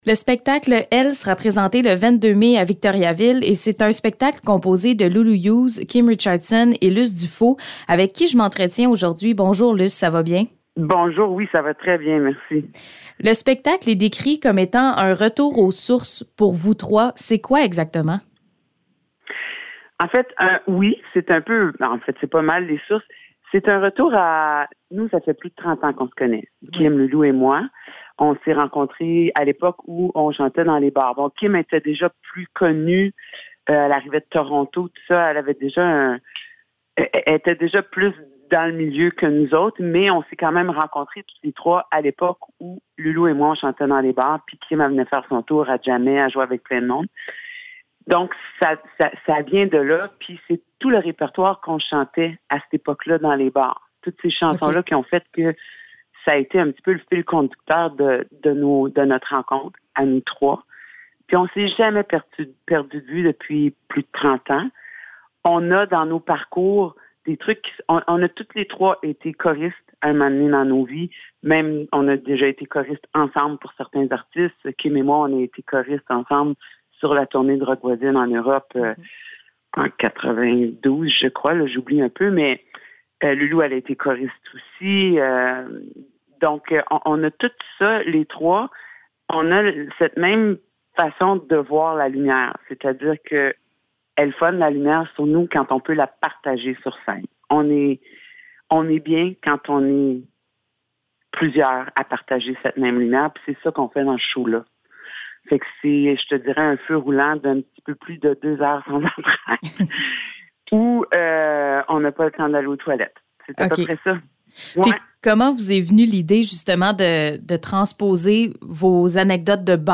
Entrevue avec Luce Dufault pour le spectacle ELLES